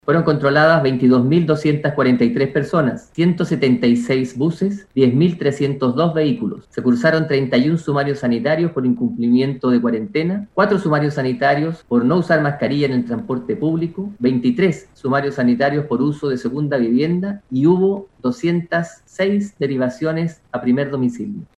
Por su parte, el jefe de la Defensa para la Región de Valparaíso, el contraalmirante Yerko Marcic, señaló la cantidad de personas fiscalizadas en los 27 puntos de controles sanitarios.